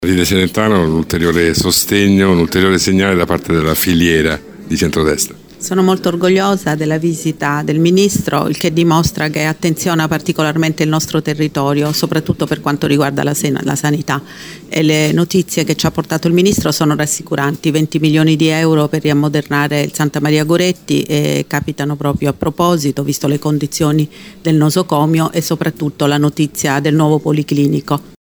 LATINAIl ministro della Salute Orazio Schillaci ha fatto visita oggi pomeriggio a Casa Latina, il point elettorale della candidata sindaco del centrodestra Matilde Celentano.